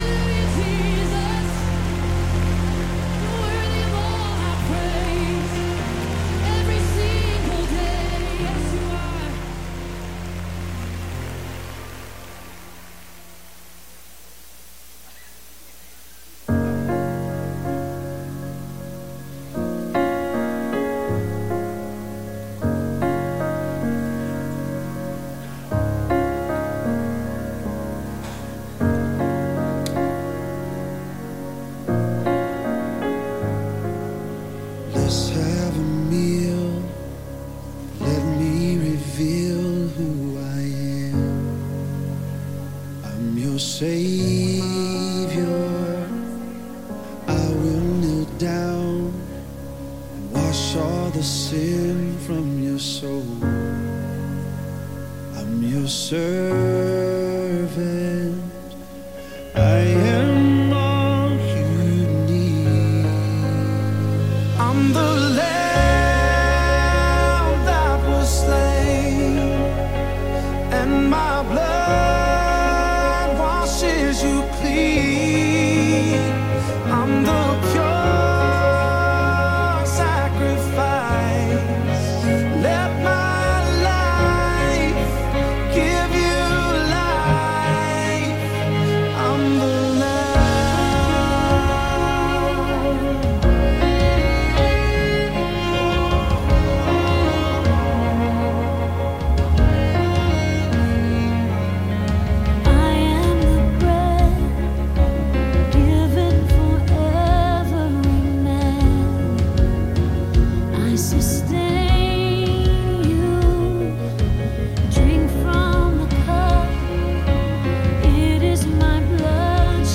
Re -Center Sermon Series
We are sure you will enjoy todays sermon.